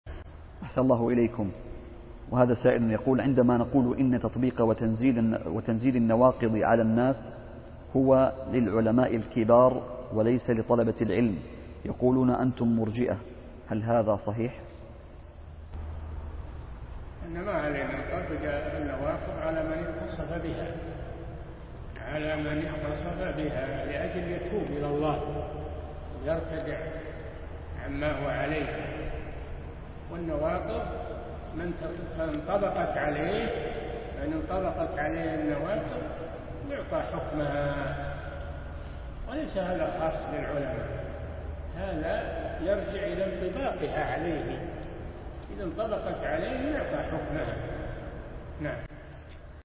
Réponse de Shaykh Al Fawzân :